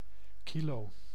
Ääntäminen
Synonyymit kilogram Ääntäminen : IPA: [kilo] Haettu sana löytyi näillä lähdekielillä: hollanti Käännös Konteksti Ääninäyte Substantiivit 1. kilogramme {m} metrologia France (Île-de-France) Paris Kilo on sanan kilogram lyhenne.